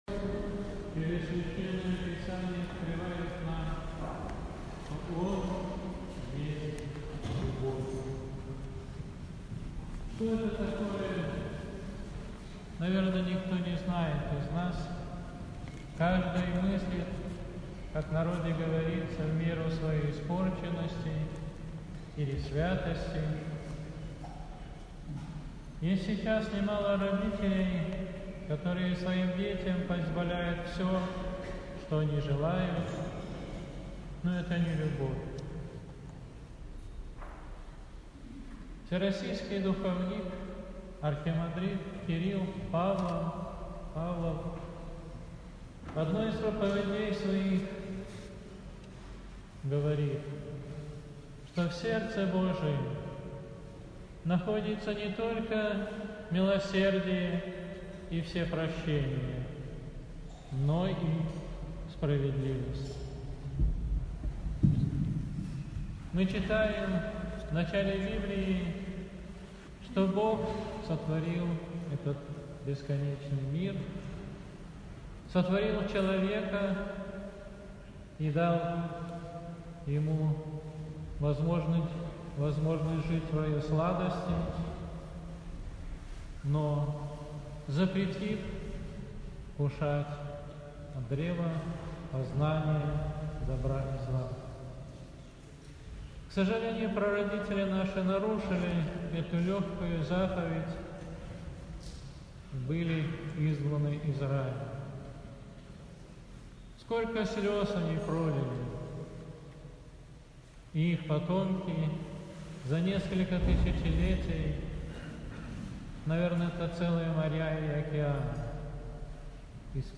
После выноса Плащаницы Архиере6й обратился к верующим со словами назидания. Прослушать проповедь.